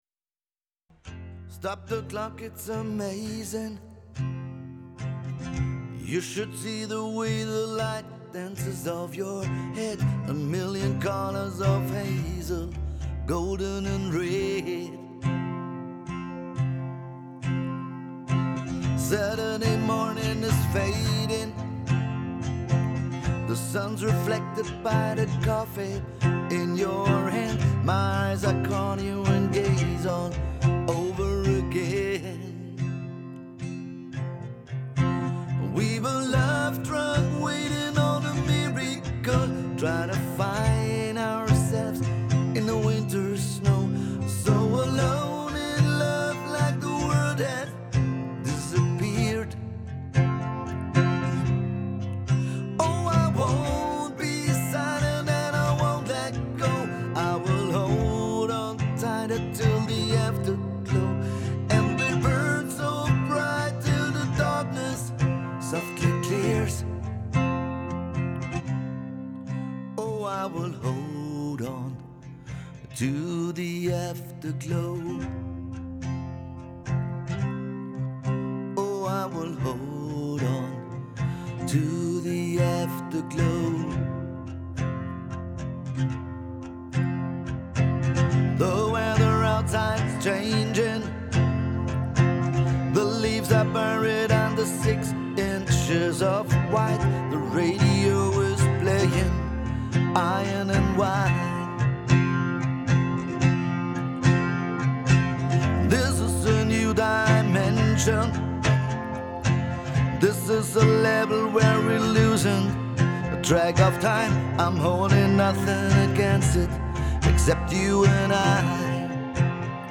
im Tannenhof 2025